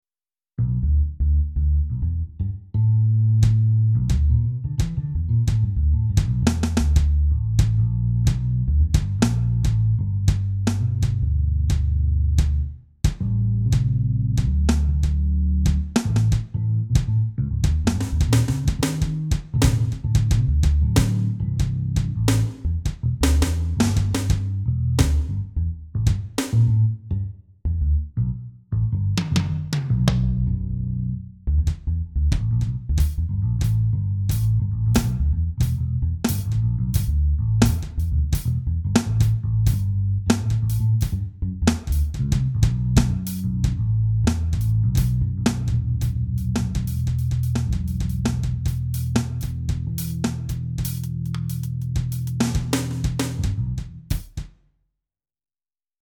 Within a week I have made these sounds, and recordings of them, using 24 bit 44.1kHz TOS link:
effbassdrum.mp3